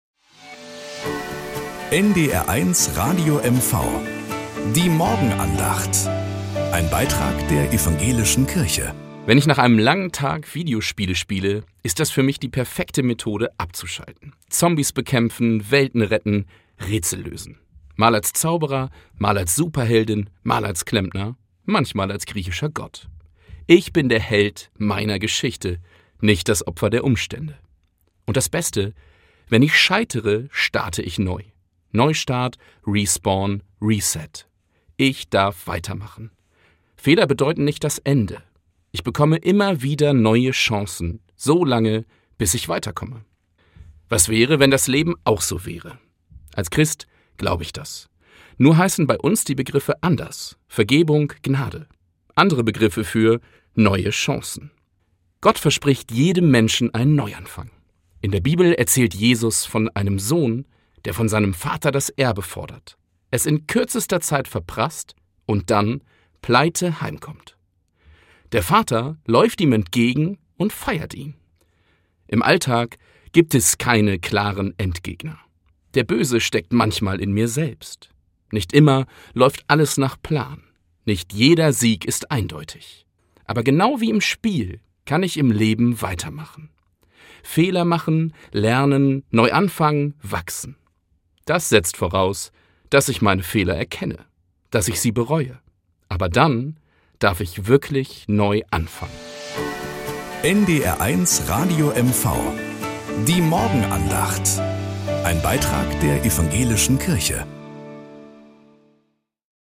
Morgenandacht bei NDR 1 Radio MV
Am Montag auf Plattdeutsch.